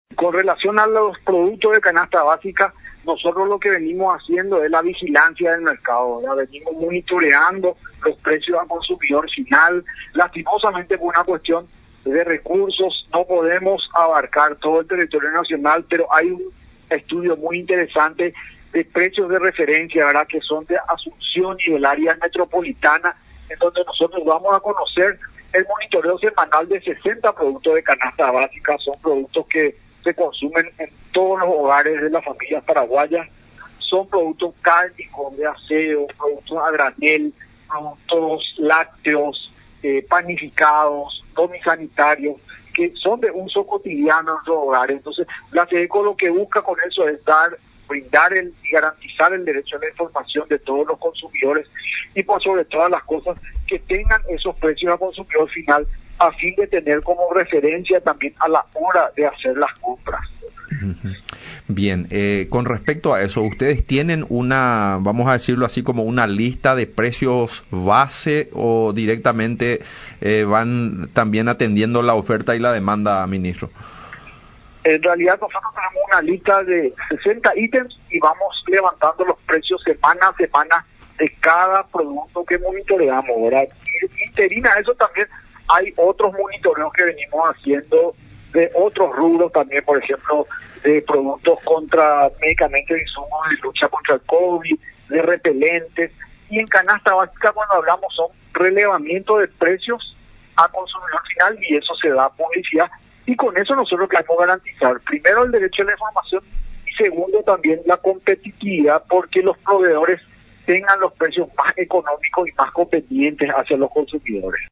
El Abg. Juan Manuel Estigarribia, ministro de SEDECO, en conversación con Radio Nacional San Pedro, explicó que el monitoreo semanal se lleva a cabo de unos 60 productos de la canasta básica que se consumen en todos los hogares de las familias paraguayas, al respecto. Instó a los proveedores a mantener precios razonables, reduciendo al mínimo el margen de ganancias de la canasta básica, considerando la situación sanitaria que atraviesa el país, hecho que requiere un compromiso de todos, otorgando la posibilidad de compra a consumidores, reduciendo así, el impacto en la economía familiar.